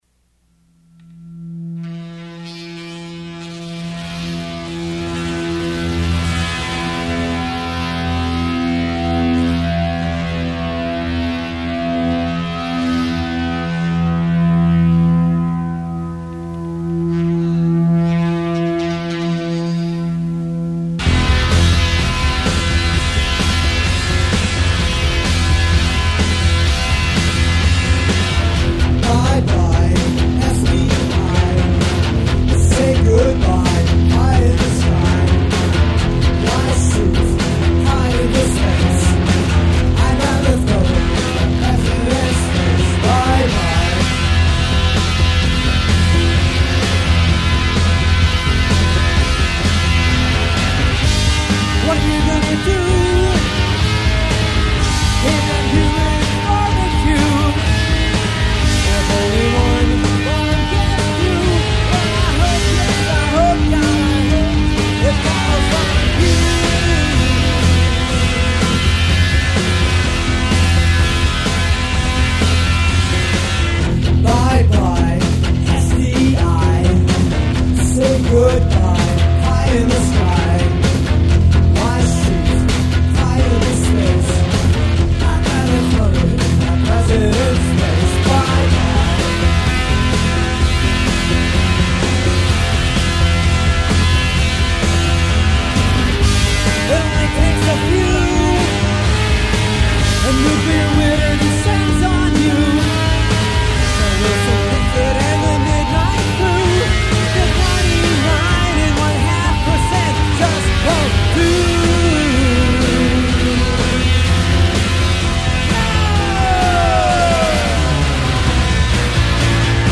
Almost heavy metal.
guitar and bass; drums